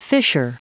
Prononciation du mot fissure en anglais (fichier audio)
Prononciation du mot : fissure